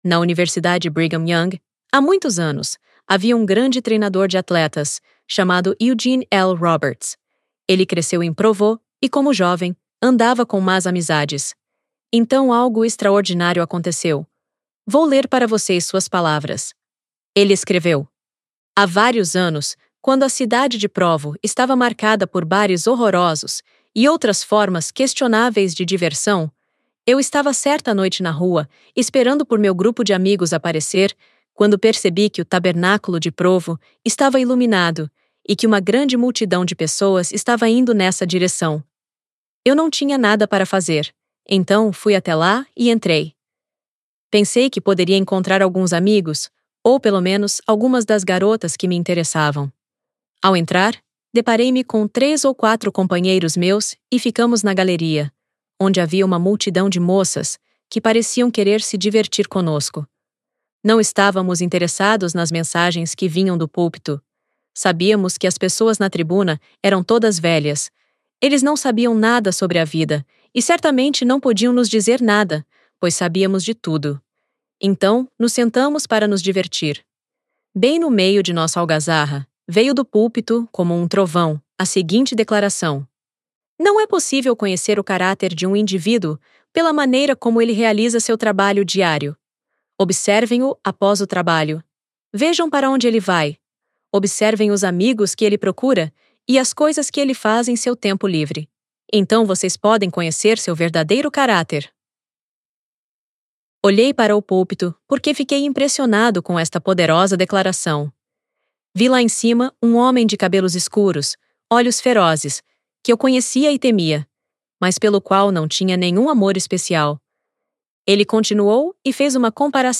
Audio recording of Transformando inimigos em amigos by Sharon Eubank
Primeira conselheira na presidência geral da Sociedade de Socorro